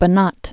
(bə-nät, bänät)